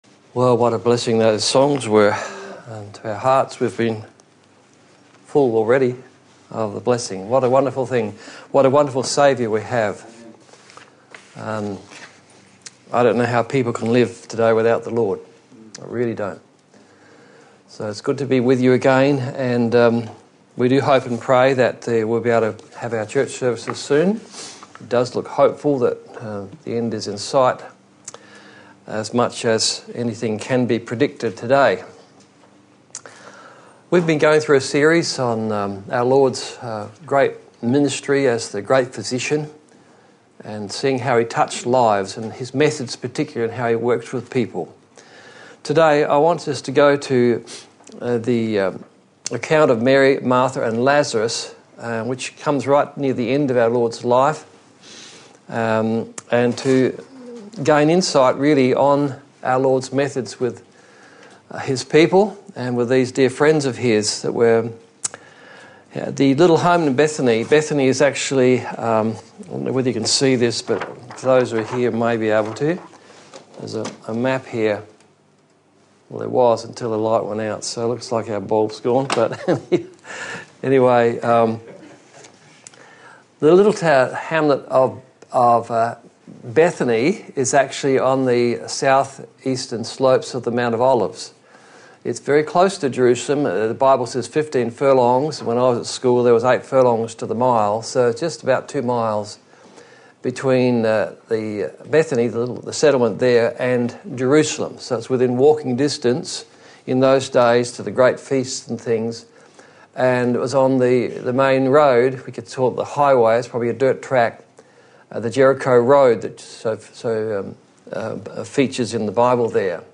Preacher
Service Type: Sunday Morning